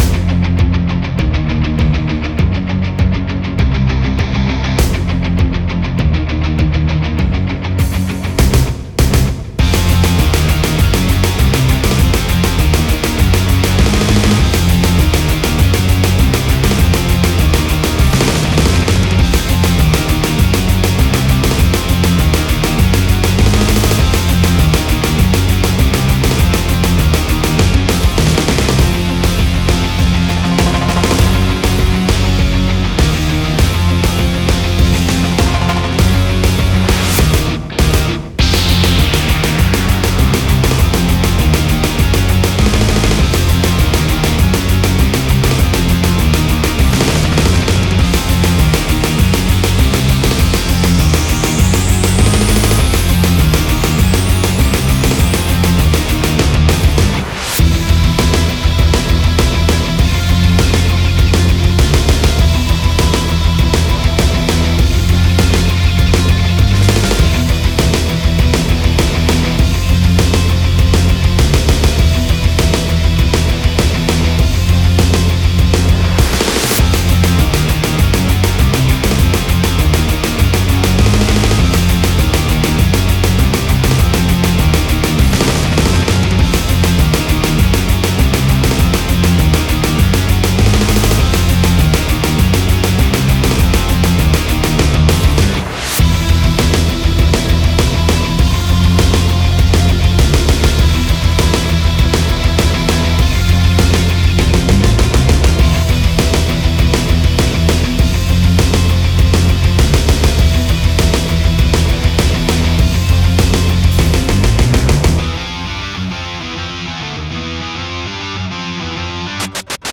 • スラッシュメタルのリフ構成をベースに、リズムはダブルキック主体
• ギターはローエンドを強調しつつ、ベースと一体感を重視
• シンセサイザーは中高域を支配し、戦闘の緊張感を増幅
• 全体のミックスは「低域の締まり」と「高域の抜け」を両立